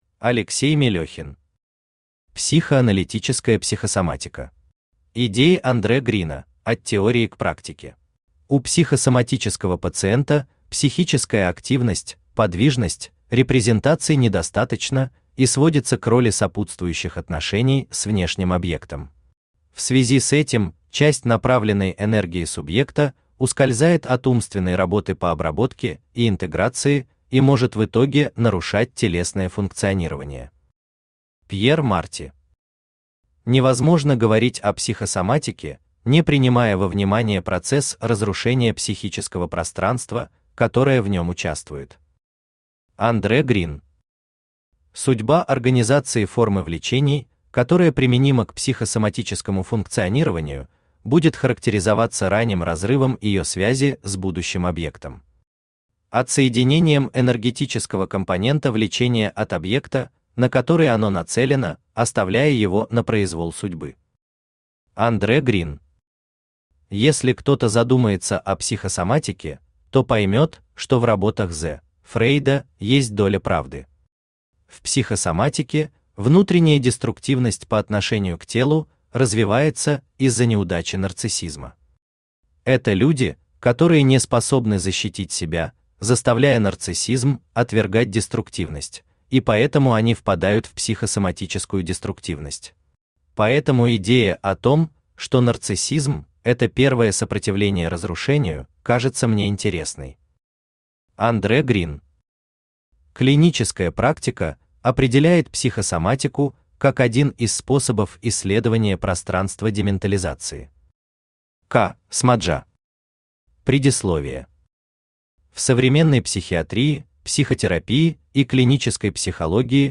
Идеи Андре Грина: от теории к практике Автор Алексей Игоревич Мелёхин Читает аудиокнигу Авточтец ЛитРес.